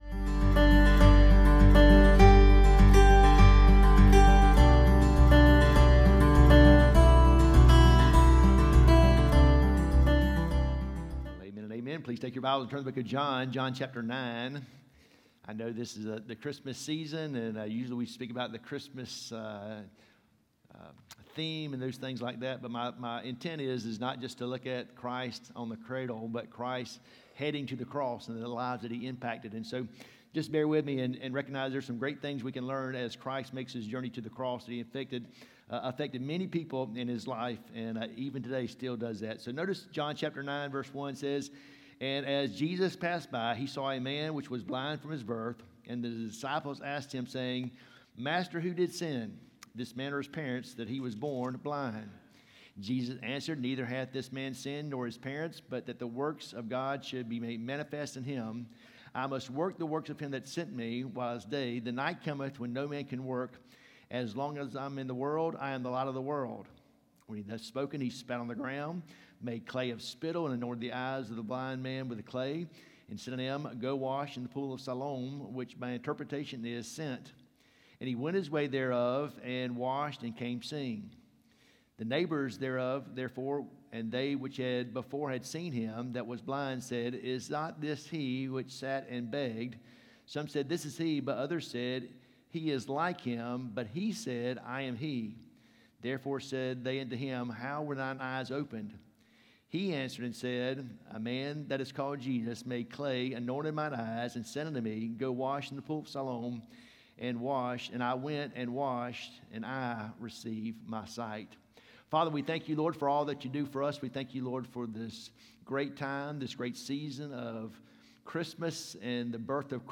Sermons 2 – Sardis Baptist Church | Worthington Springs, FL
From Series: "Sunday AM"